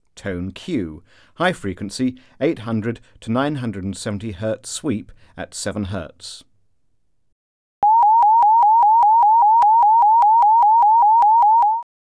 Alert Tone: Q